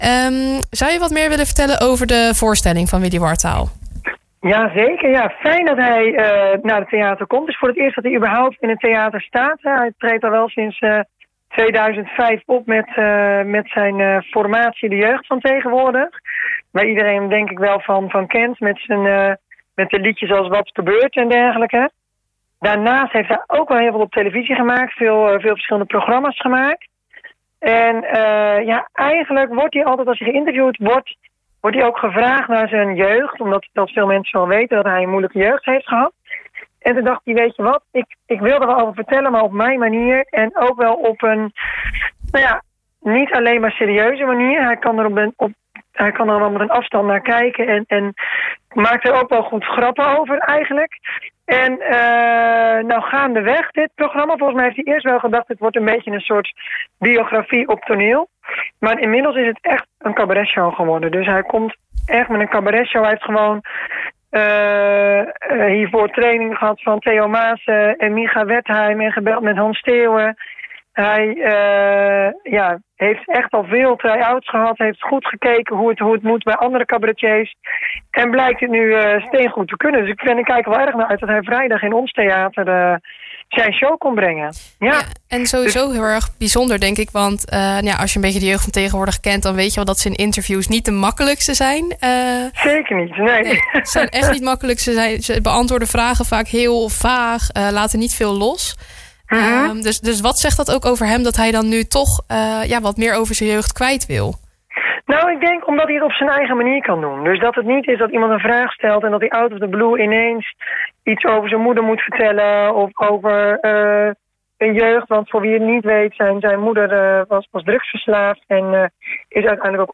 in gesprek